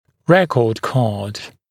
[‘rekɔːd kɑːd][‘рэко:д ка:д]учетная карточка